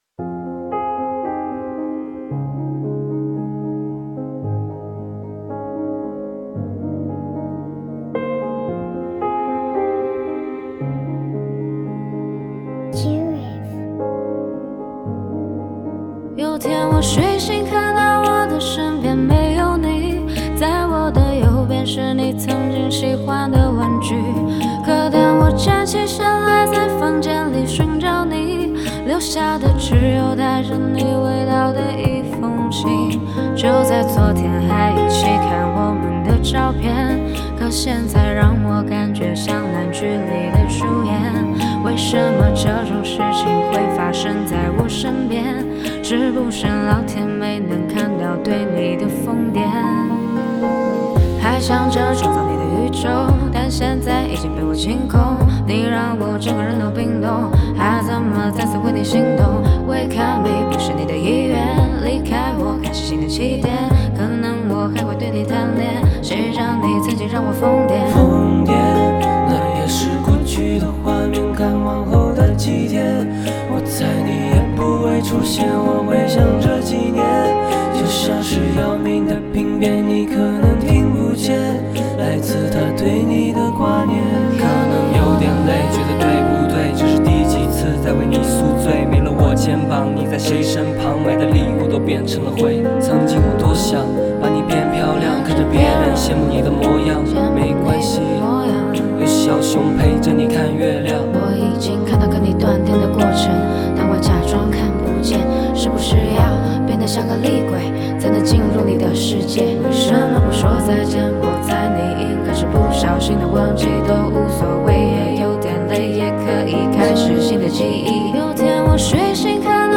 Ps：在线试听为压缩音质节选，体验无损音质请下载完整版
女声版